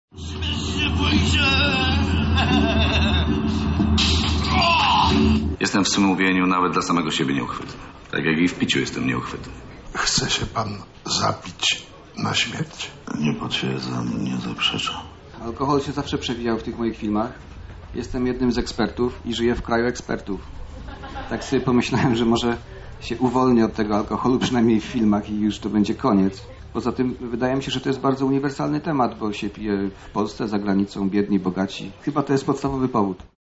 Ostatniego dnia imprezy z fanami rozmawiał Wojciech Smarzowski. Reżyser zdradził kulisy powstawania swojego nowego filmu „Pod Mocnym Aniołem”.